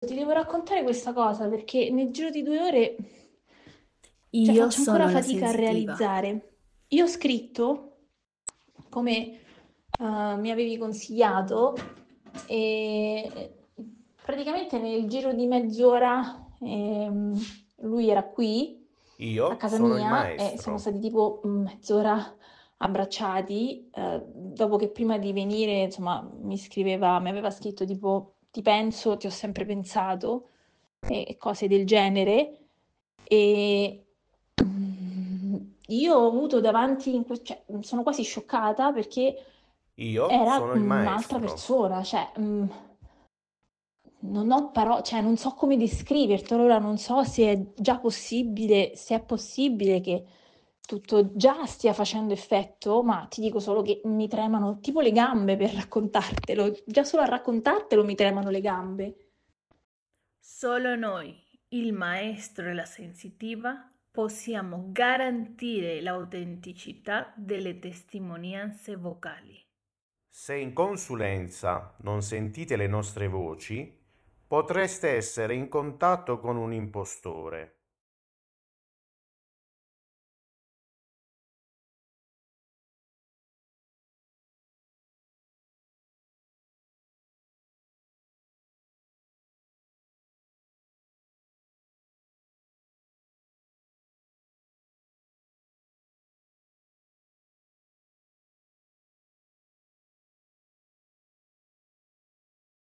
TESTIMONIANZA VOCALE DEL CLIENTE TORNA ALLA LISTA COMPLETA